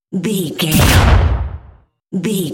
Dramatic hit explosion electricity
Sound Effects
heavy
intense
dark
aggressive